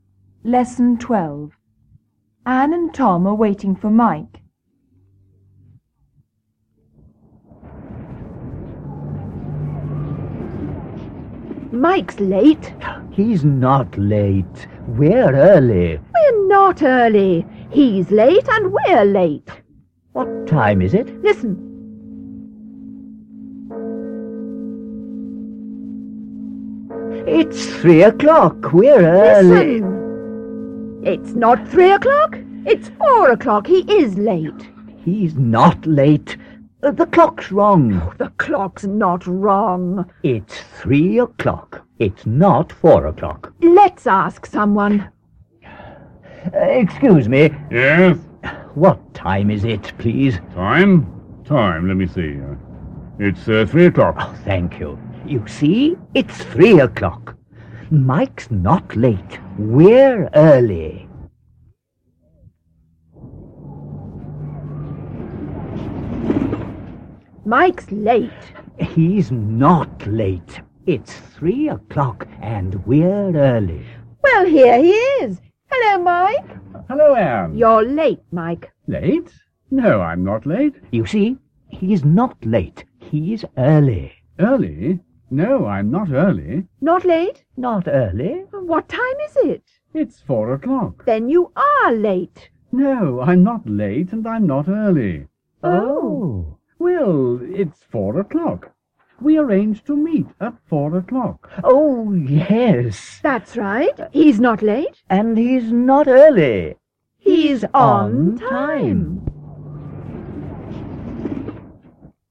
Dialogo: It's not 3 o'clock. He's not late